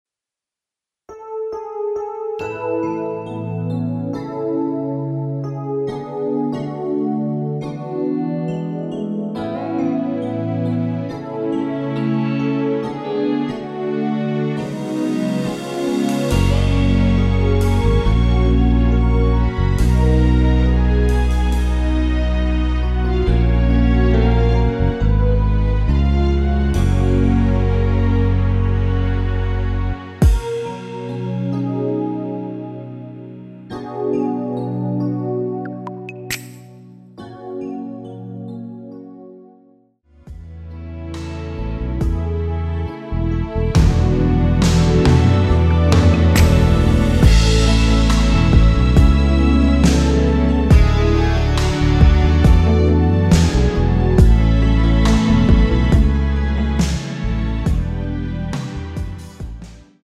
F#
◈ 곡명 옆 (-1)은 반음 내림, (+1)은 반음 올림 입니다.
앞부분30초, 뒷부분30초씩 편집해서 올려 드리고 있습니다.
중간에 음이 끈어지고 다시 나오는 이유는